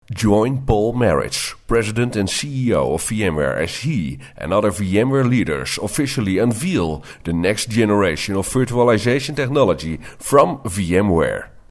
VMware Ringtones